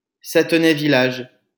来自 Lingua Libre 项目的发音音频文件。 语言 InfoField 法语 拼写 InfoField Sathonay-Village 日期 2018年12月22日 来源 自己的作品